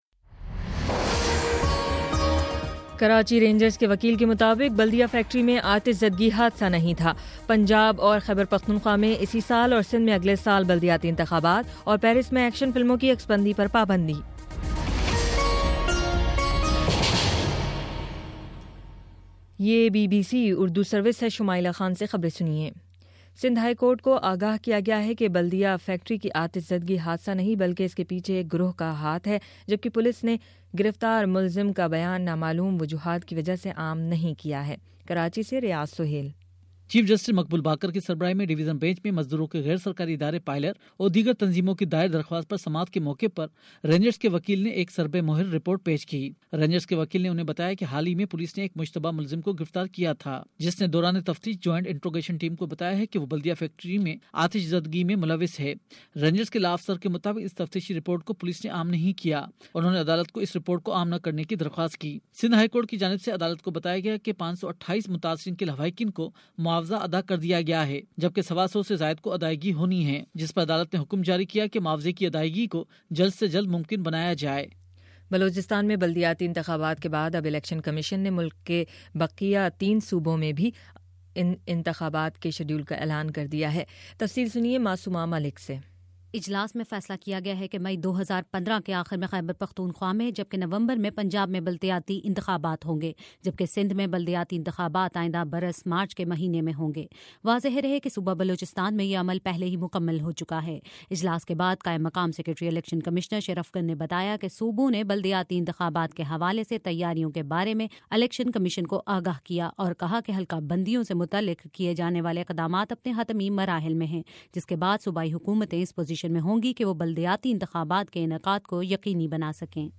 فروری06 : شام سات بجے کا نیوز بُلیٹن